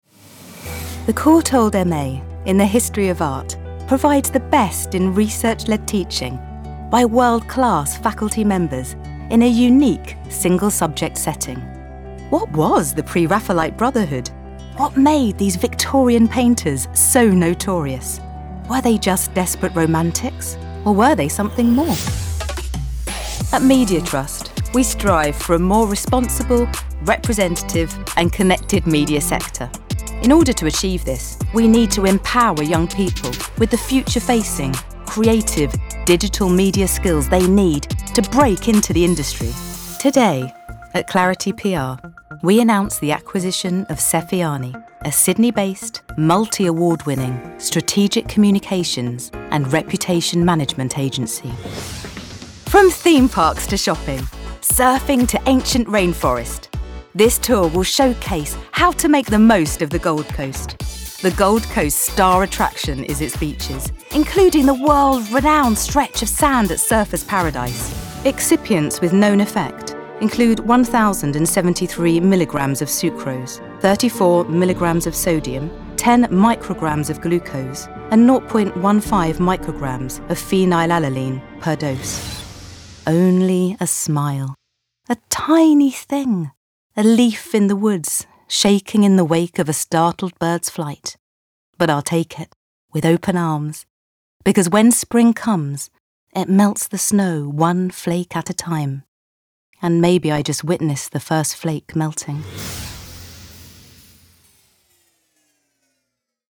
British female VO with warm, confident style
Narration reel
English RP, London, Bristol
Middle Aged